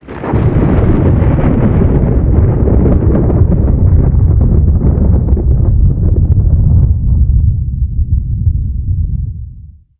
thunder2.wav